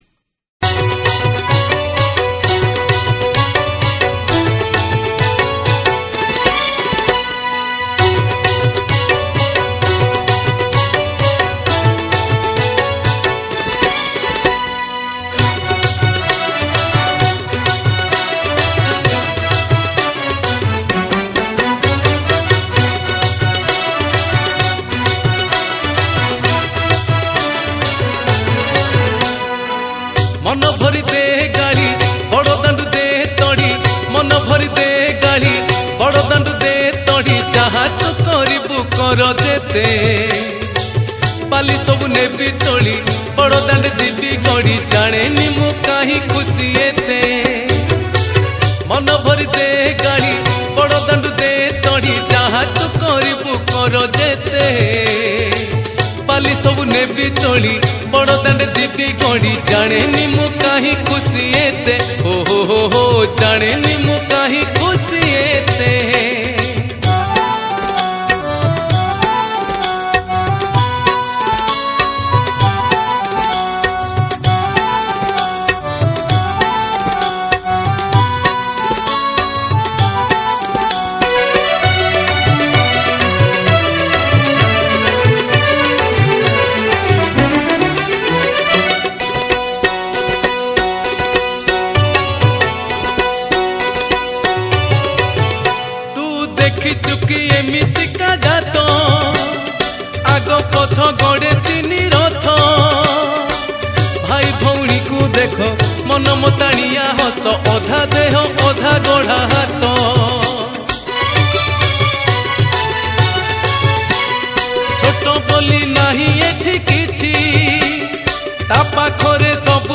Odia Bhajan Ratha Yatra Special